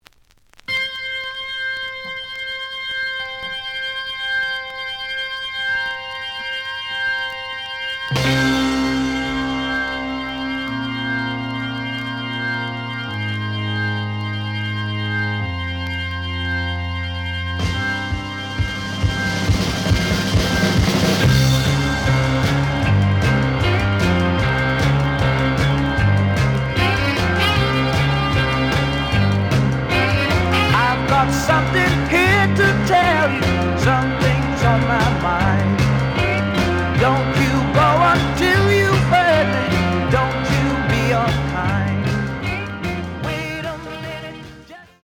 試聴は実際のレコードから録音しています。
●Format: 7 inch
●Genre: Rock / Pop